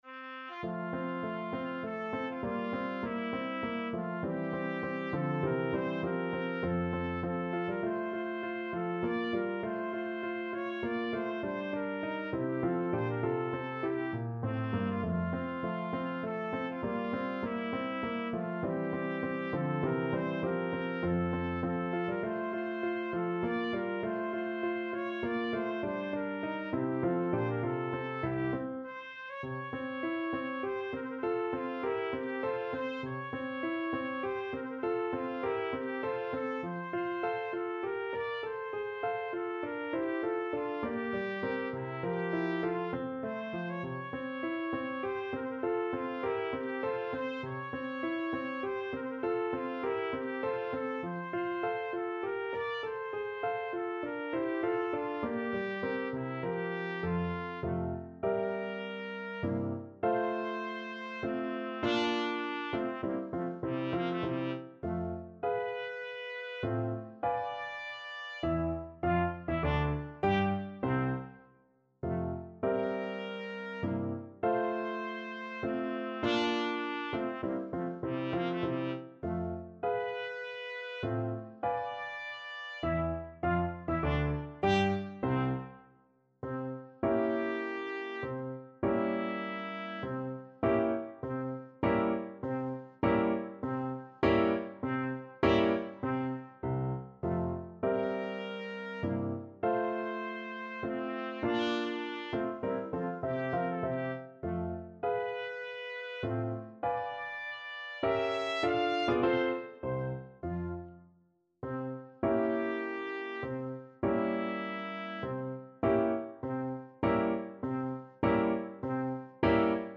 3/4 (View more 3/4 Music)
Menuetto Moderato e grazioso
Classical (View more Classical Trumpet Music)